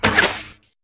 longbowRelease.ogg